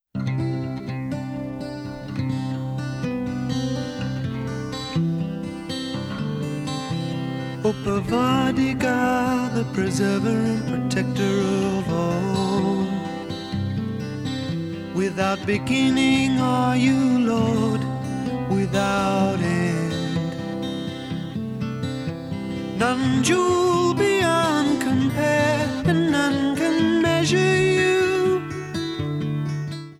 sounds excellent.